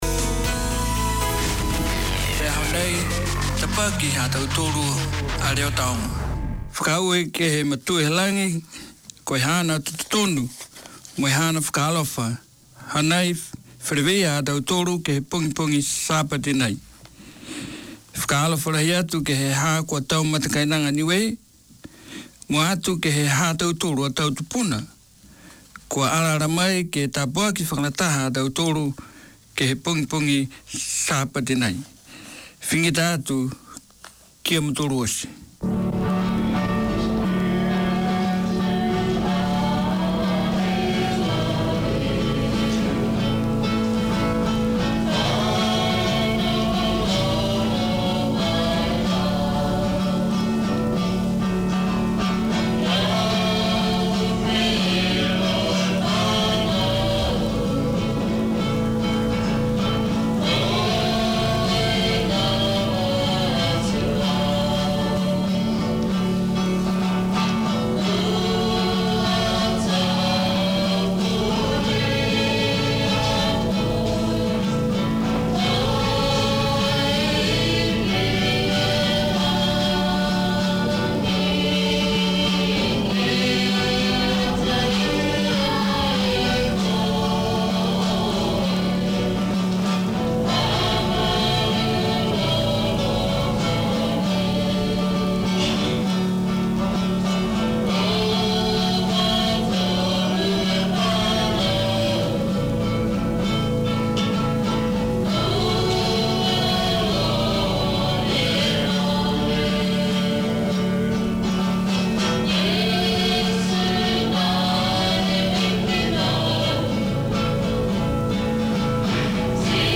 Niuean Church Services
This is co-operative airtime shared between three Niuean Christian churches from around the Auckland region. The churches come to your place with a weekly rotation of services including preaching, singing, playing and praying. There are gospel songs and gospel lessons, praise and testifying.